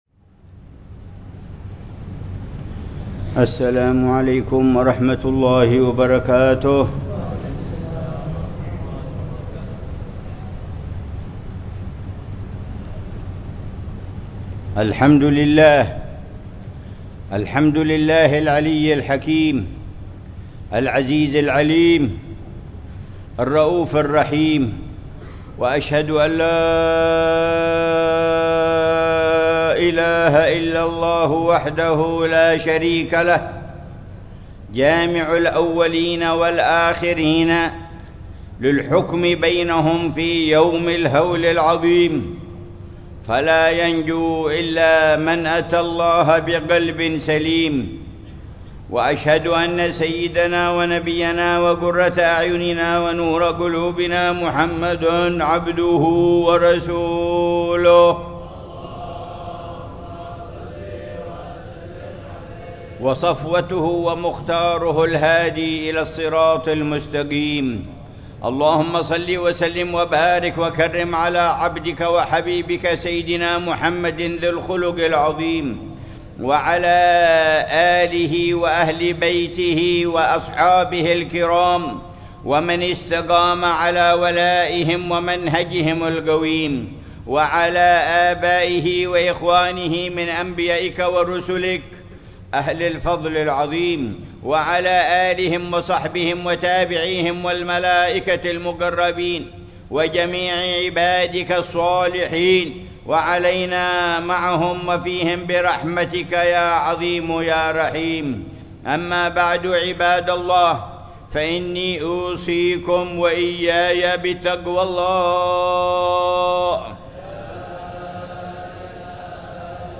خطبة الجمعة للعلامة الحبيب عمر بن محمد بن حفيظ في جامع الروضة، بحارة الروضة بعيديد، مدينة تريم، 27 جمادى الأولى 1446هـ بعنوان: